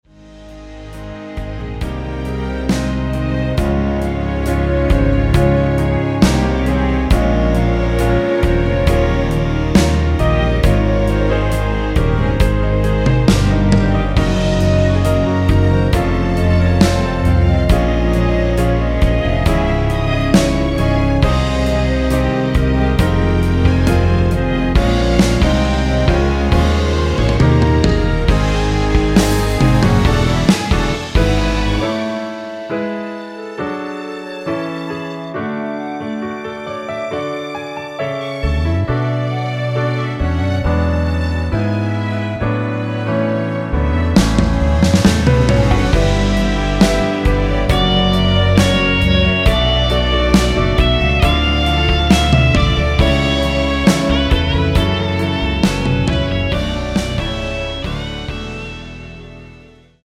1절후 후렴으로 진행되게 편곡된 멜로디 MR 입니다.
원키에서(-3)내린 (1절+후렴)으로 편곡된 멜로디 포함된 MR입니다.(미리듣기및 본문가사 참조)
Ab
앞부분30초, 뒷부분30초씩 편집해서 올려 드리고 있습니다.